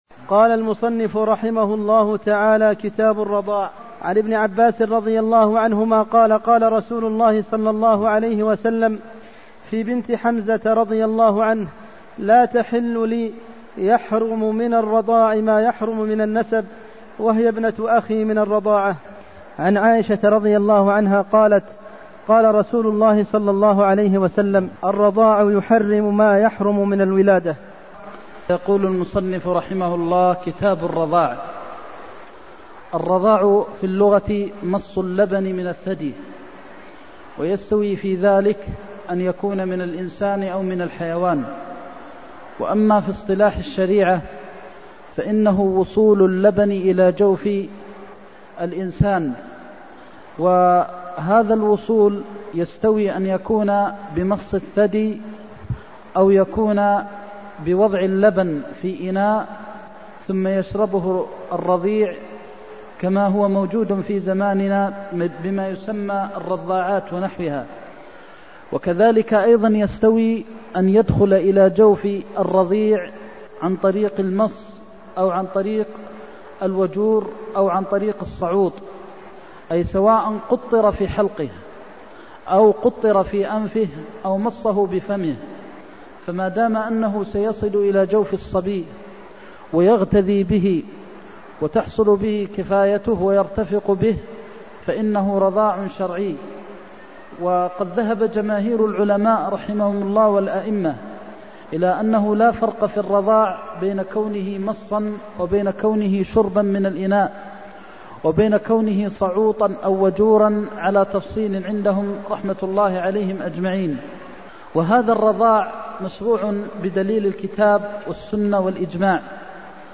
المكان: المسجد النبوي الشيخ: فضيلة الشيخ د. محمد بن محمد المختار فضيلة الشيخ د. محمد بن محمد المختار يحرم من الرضاع ما يحرم من النسب (314)